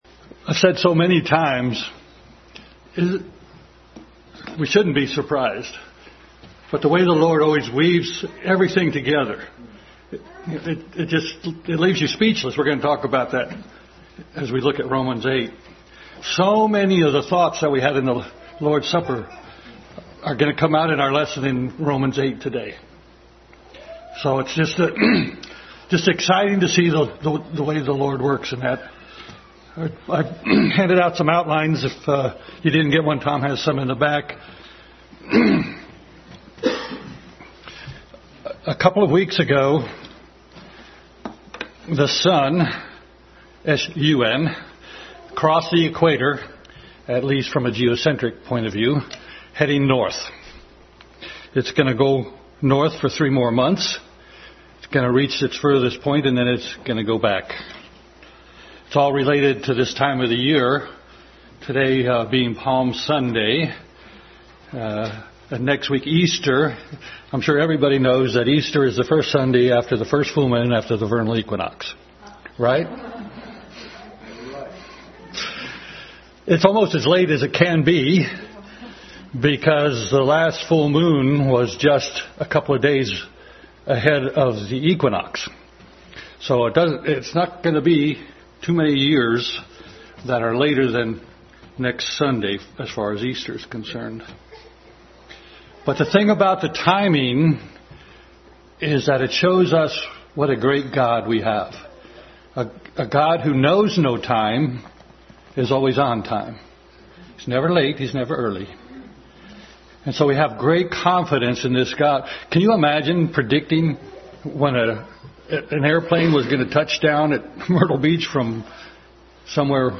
Adult Sunday School Class continued study in Romans.
2 Corinthians 3:18 Service Type: Sunday School Adult Sunday School Class continued study in Romans.